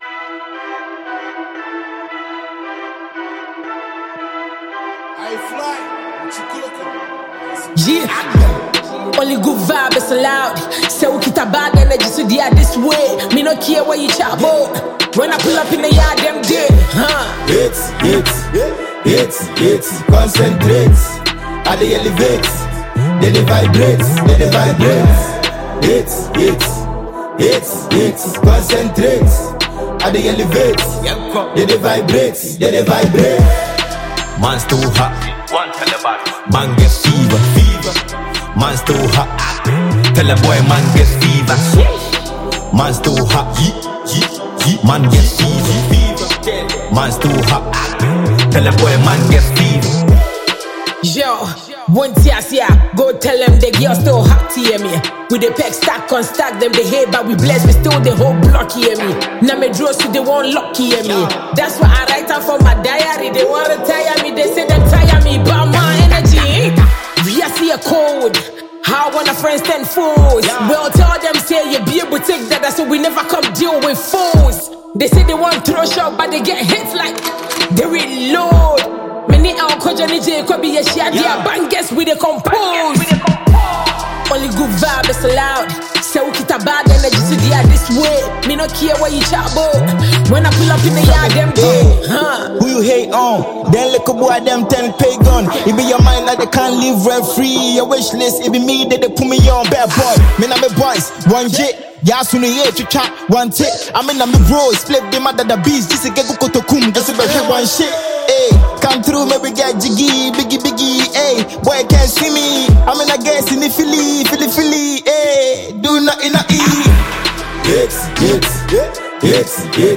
Multi-talented Ghanaian female rapper